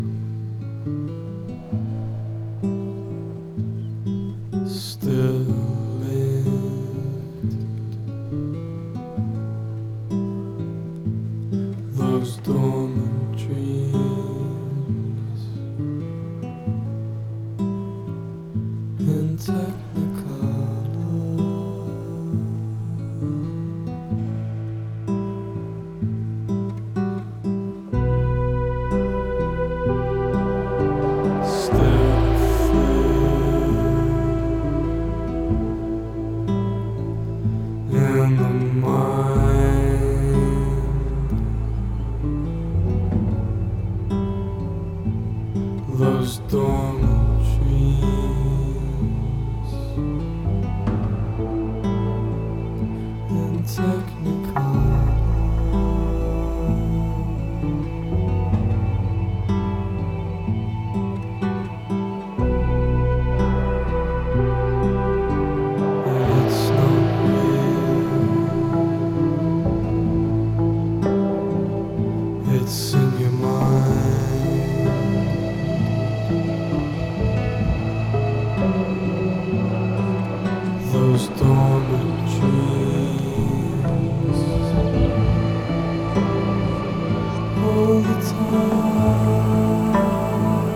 Bristol’s four-piece outfit
Wave Ambient Rock